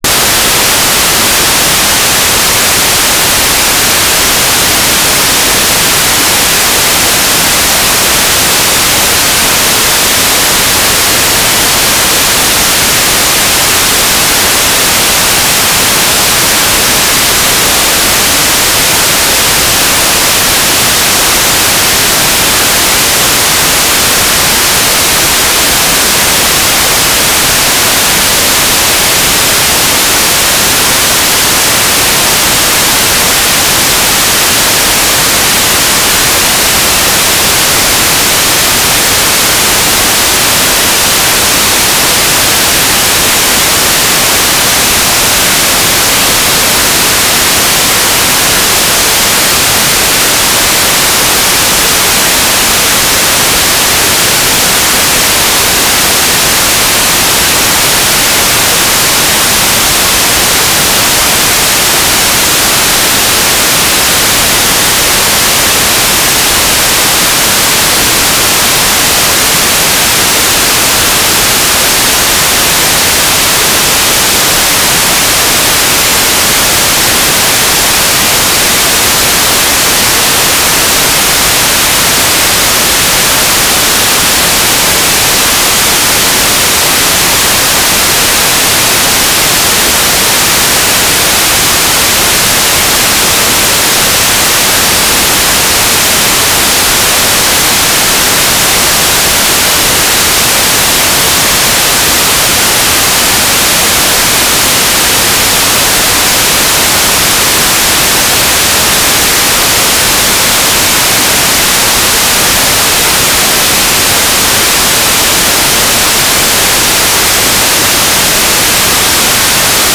"waterfall_status": "without-signal",
"transmitter_description": "Mode V/U FM - Voice Repeater CTCSS 67.0 Hz",
"transmitter_mode": "FM",